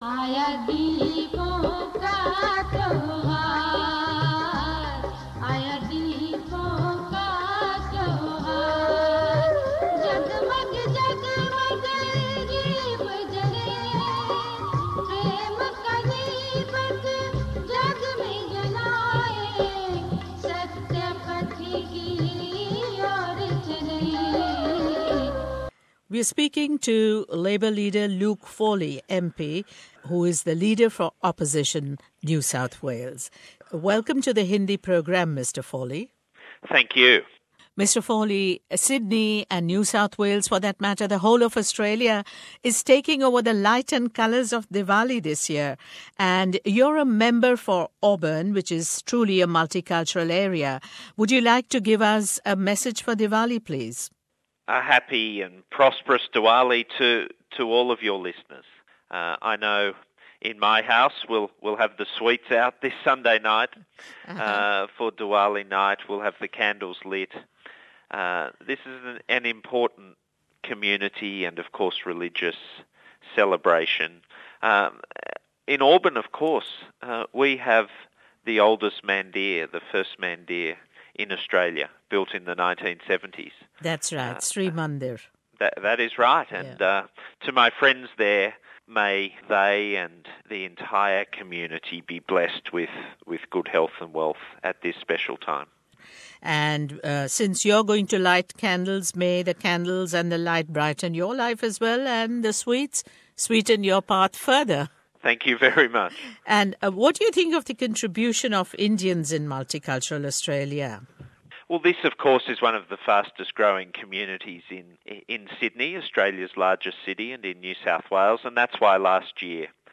Diwali Wishes From NSW Opposition Leader Luke Foley
NSW संसद के विपक्षी नेता लुक फोले दे रहे हैं हमें दिवाली की मंगल कामनाएं और वे सराहना करते हैं मूल भारतीय लोगों के योगदान की।